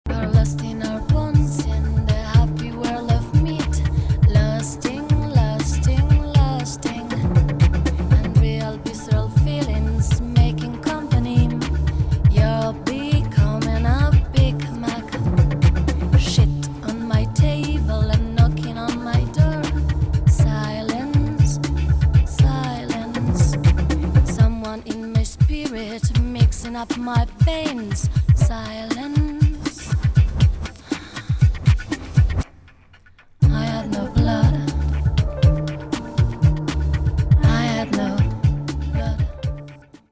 [ DOWNBEAT / BREAKBEAT ]
ディープ＆メロウなダウンテンポ・ブレイクビーツ・アルバム！！